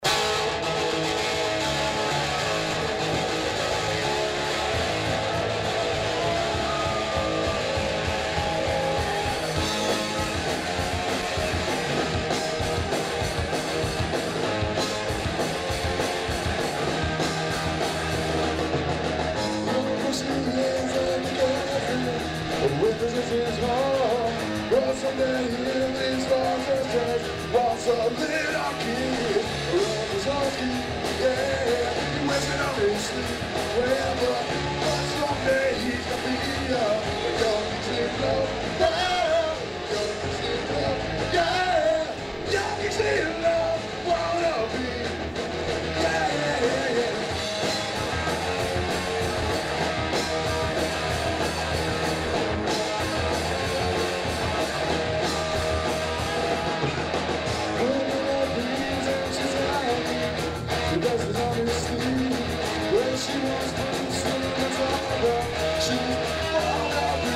Live at the Bottleneck..(well it was at the time)